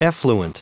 Prononciation du mot effluent en anglais (fichier audio)
Prononciation du mot : effluent